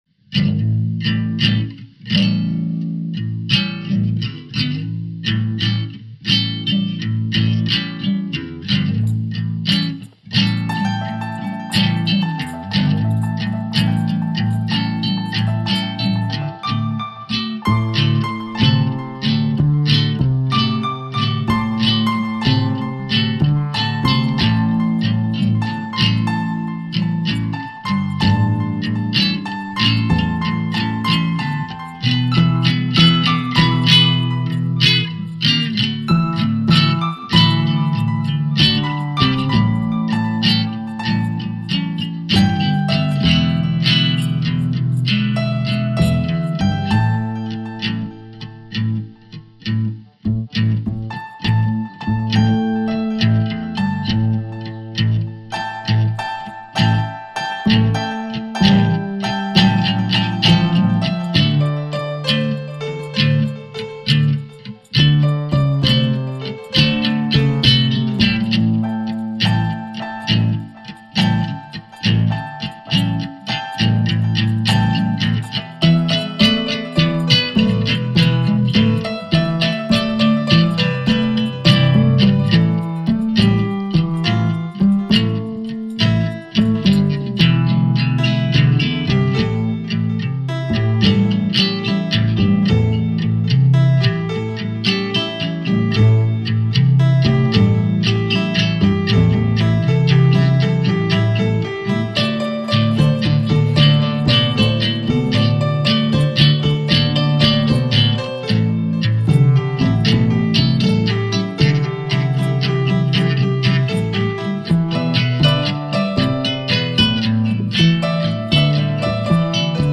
RnB
NuJazz